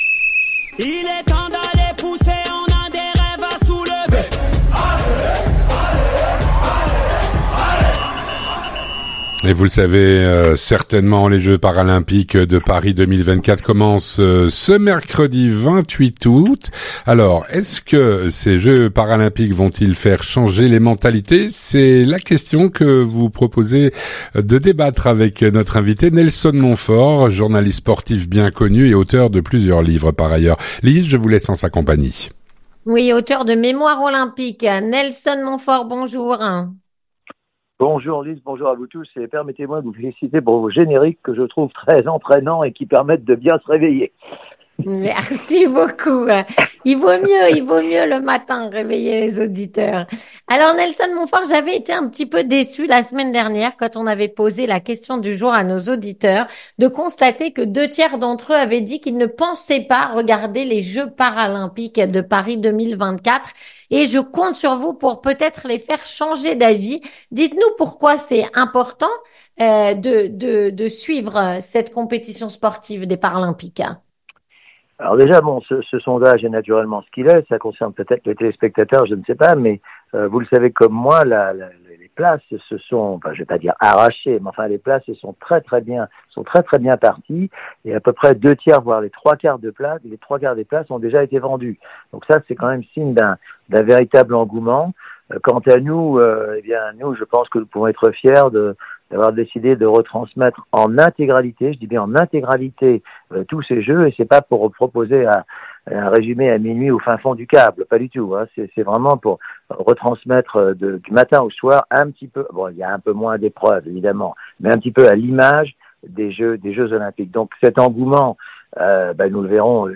Avec Nelson Montfort, journaliste sportif et auteur de plusieurs livres dont le dernier : "Mémoire olympique".